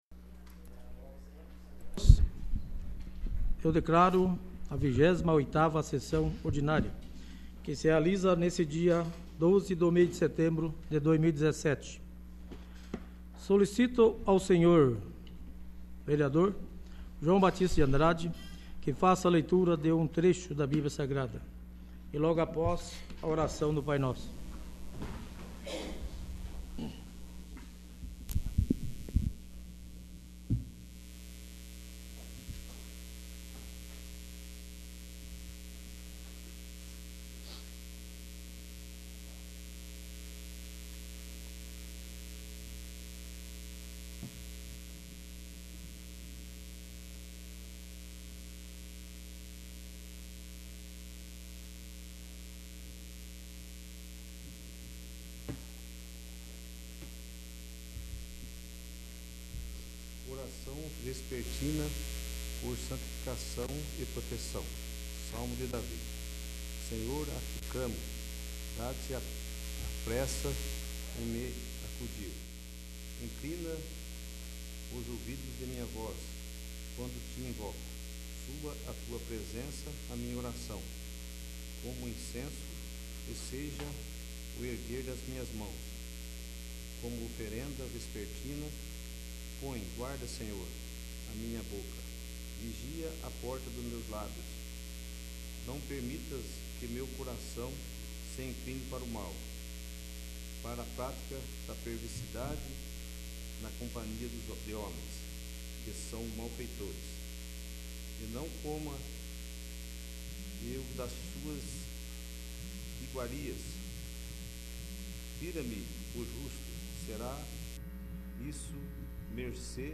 28º. Sessão Ordinária